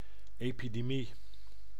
Ääntäminen
IPA: /ˌeːpidəˈmi/